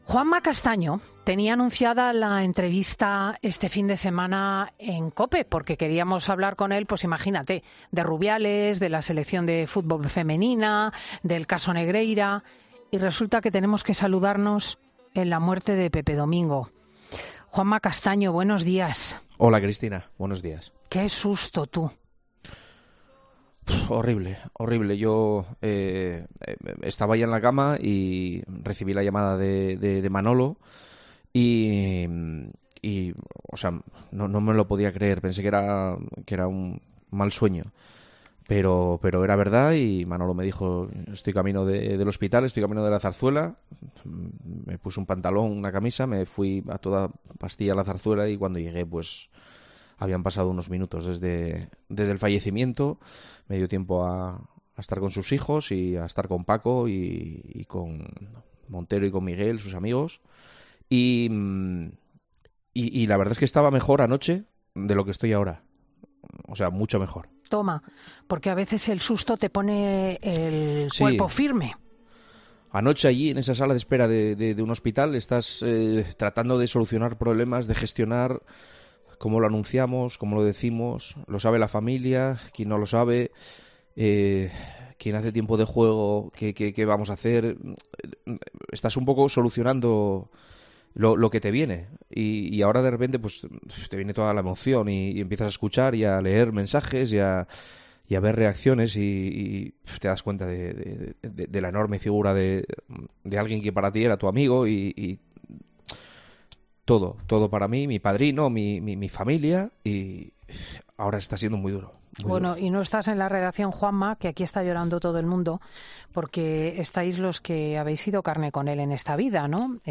El director de 'El Partidazo de COPE' ha despedido a la leyenda de 'Tiempo de Juego' emocionado con Cristina López Schlichting